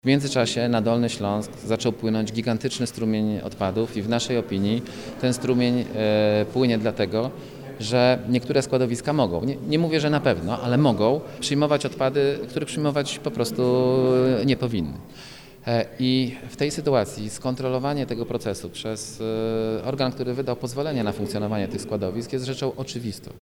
– Od trzech lat zarząd województwa odmawia przeprowadzenia kontroli składowisk odpadów mimo, że ustawa upoważnia do takich działań – wyjaśnia Patryk Wild – Radny Sejmiku.